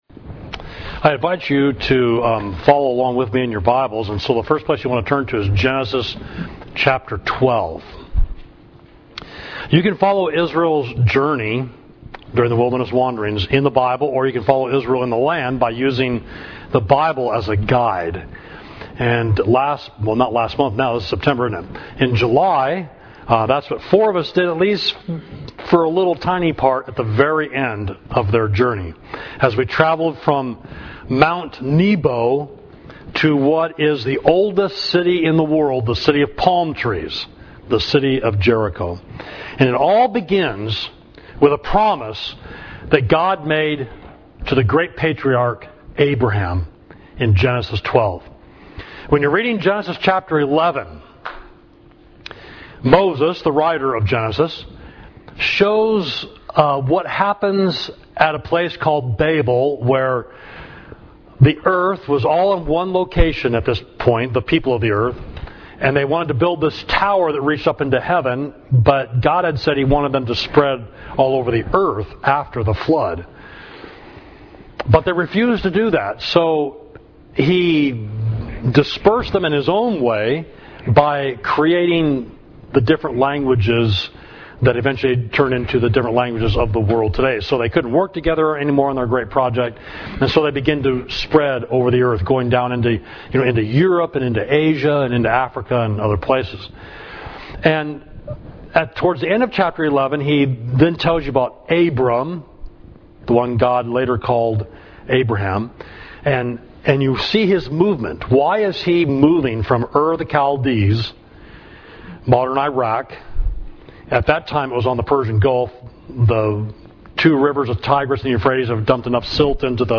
Sermon: From Mount Nebo to Jericho – Part 1